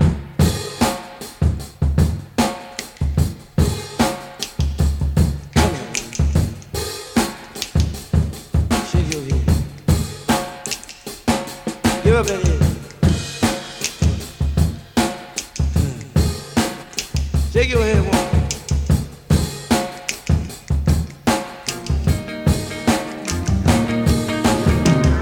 76 Bpm Drum Loop C# Key.wav
Free drum groove - kick tuned to the C# note.
76-bpm-drum-loop-c-sharp-key-PfE.ogg